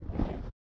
PixelPerfectionCE/assets/minecraft/sounds/mob/polarbear/step3.ogg at mc116